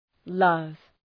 Προφορά
{lʌv}